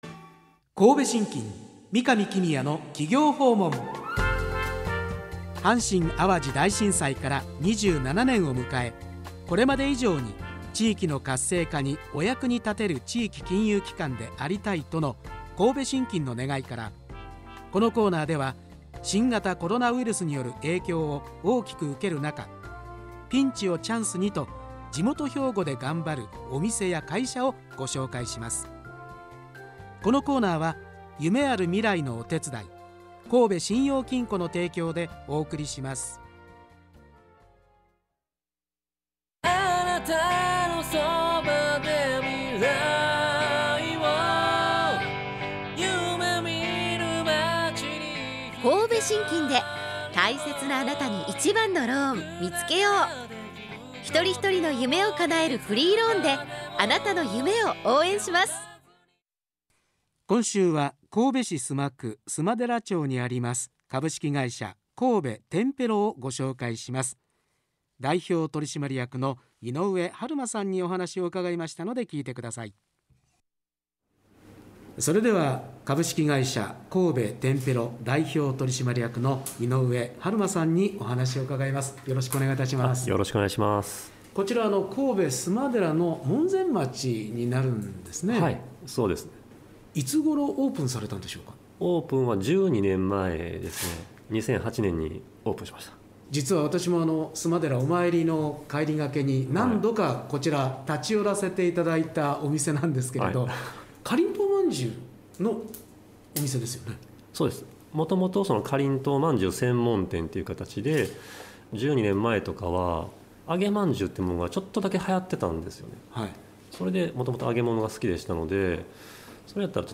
【 2022年2月22日放送回…放送音声 】